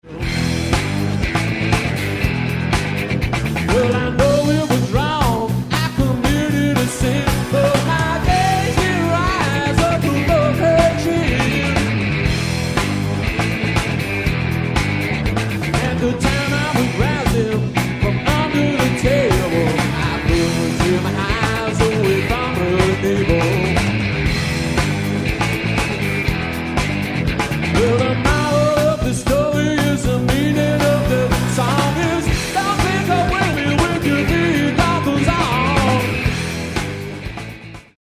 sweaty pounding rhythms